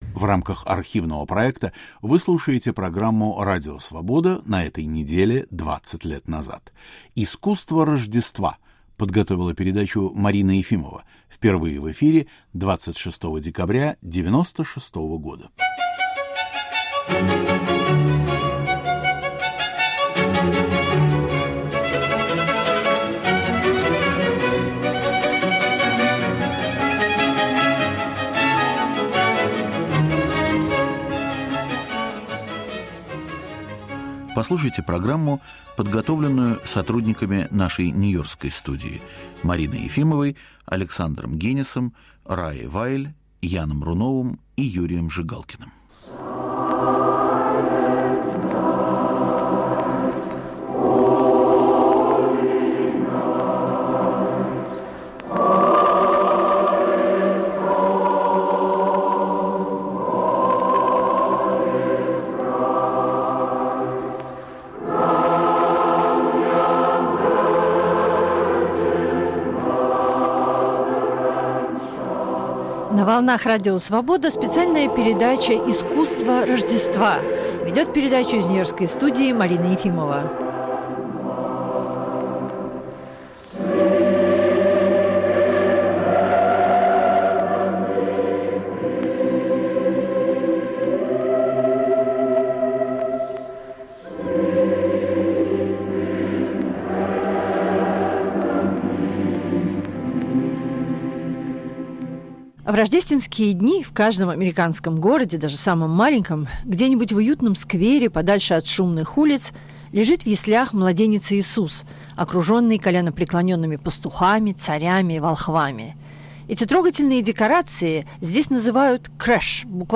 Звучит "рождественская" музыка.